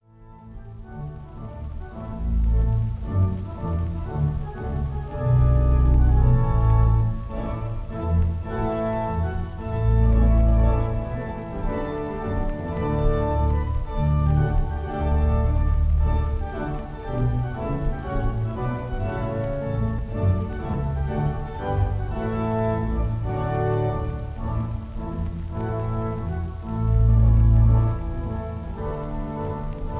at the organ of St. Marks,
North Audley Street, London
Recorded on May 7, 1947